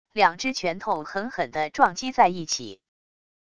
两只拳头狠狠的撞击在一起wav音频